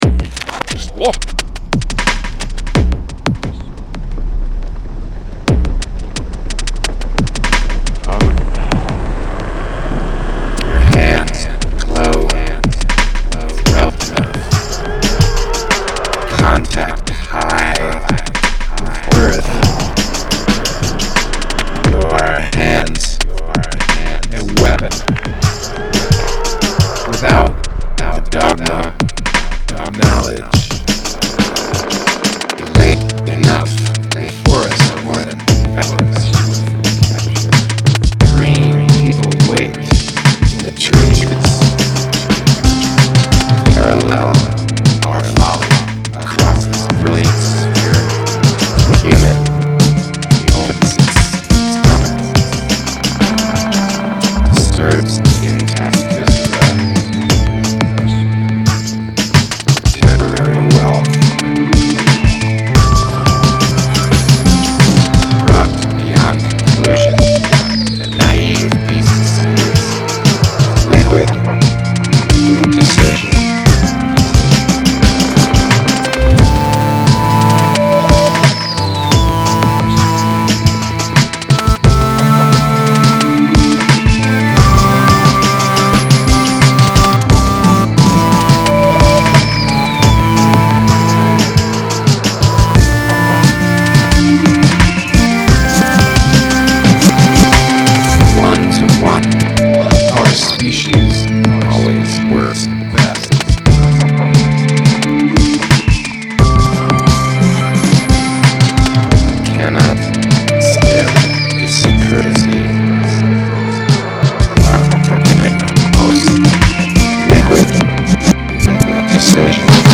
hmm...not necessarily a conventional song, but it's the only one i've finished since moving into the new space. but, there's guitar, harp, and plenty of nord modular in it...as well as a bit of vocals done with mixer + shure: